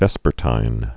(vĕspər-tīn) also ves·per·ti·nal (vĕspər-tīnəl)